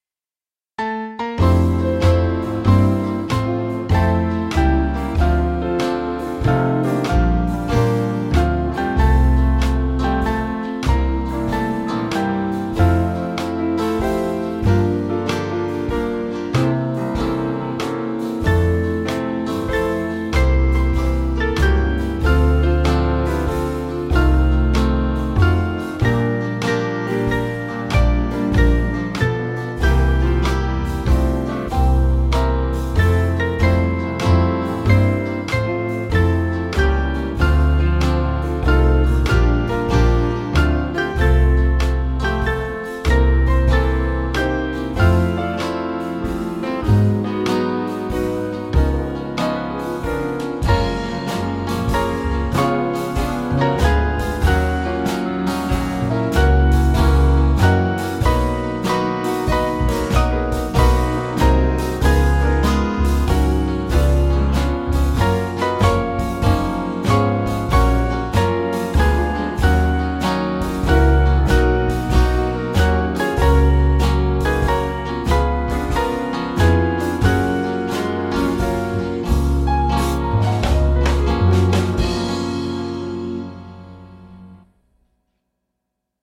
Swing Band